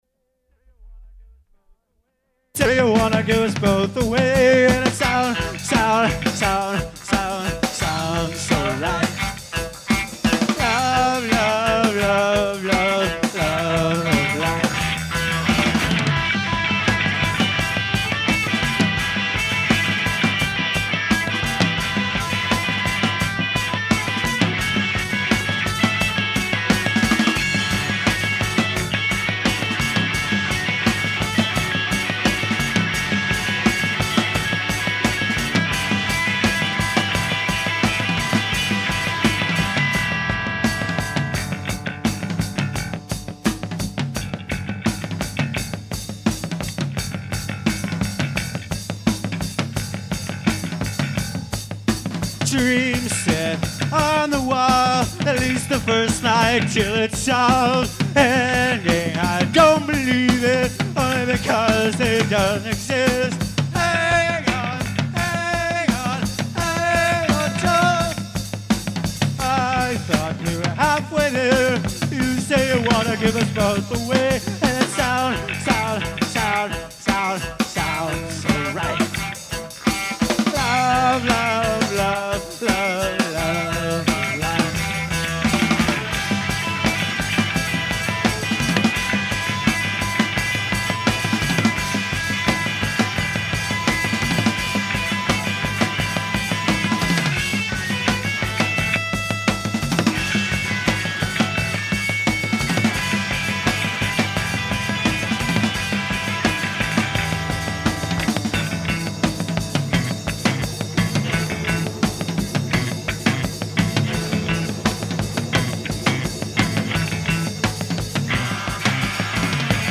Recording of a live performance